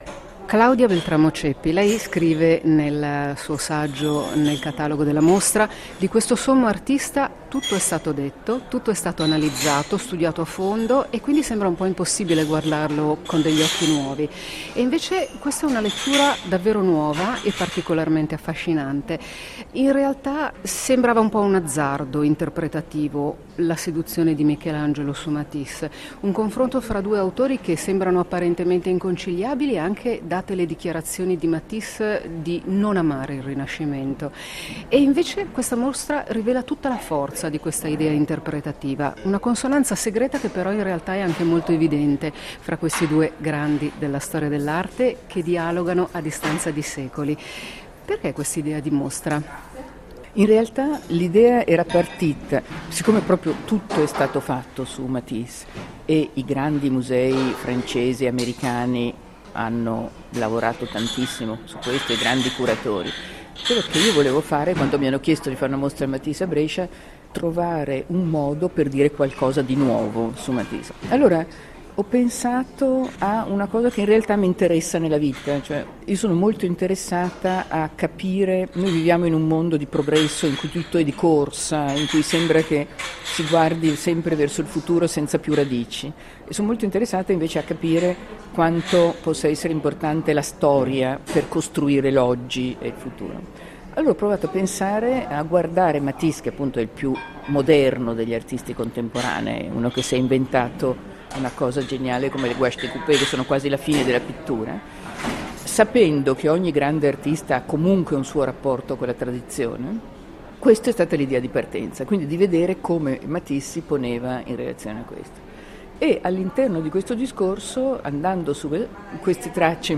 L'intervista integrale alla curatrice della mostra